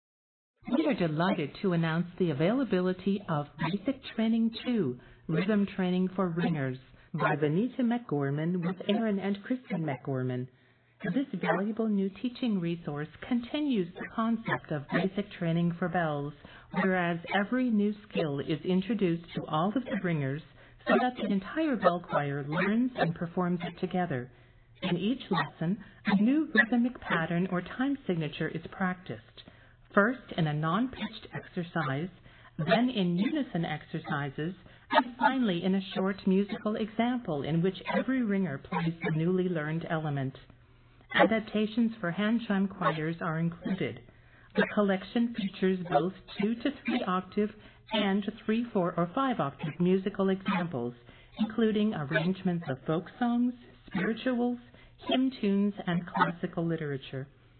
Octaves: 2-5